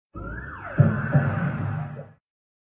Descarga de Sonidos mp3 Gratis: dibujos animados 18.